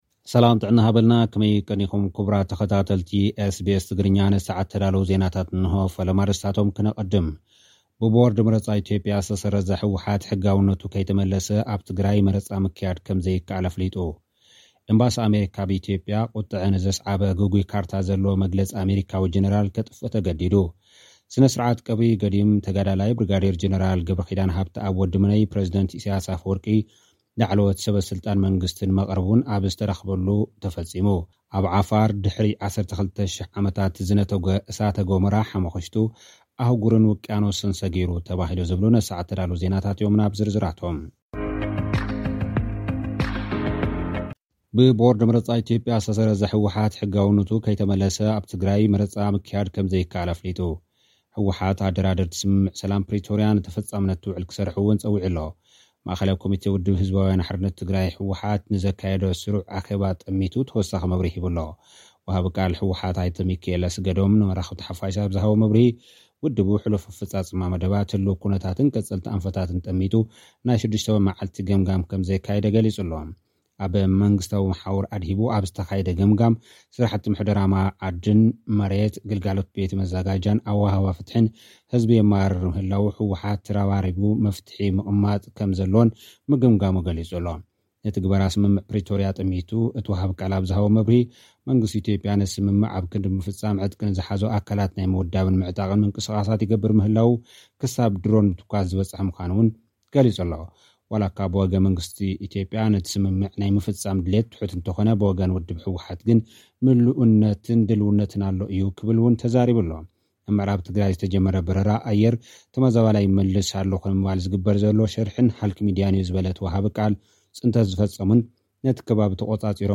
ኤምባሲ ኣመሪካ ኣብ ኢትዮጵያ: ቁጠዐ ንዘስዓበ ግጉይ ካርታ ዘለዎ መግለፂ ኣሜሪካዊ ጀነራል ከጥፍእ ተገዲዱ። (ጸብጻብ)